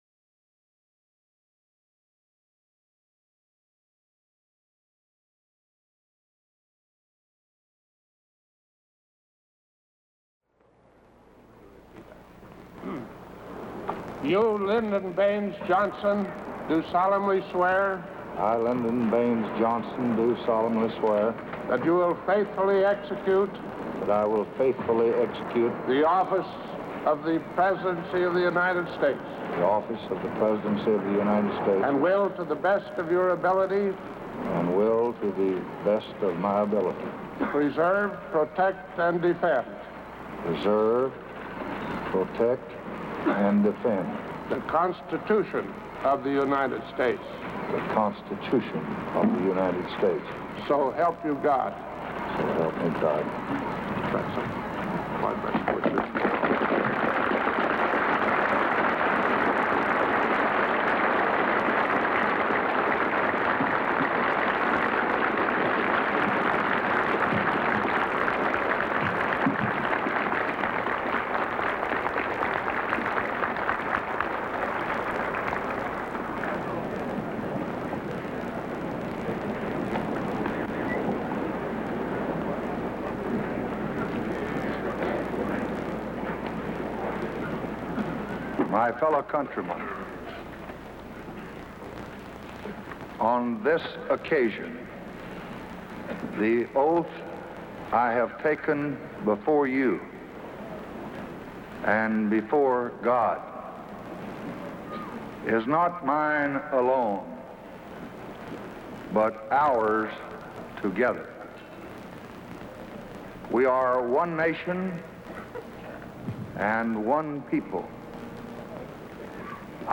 January 20, 1965: Inaugural Address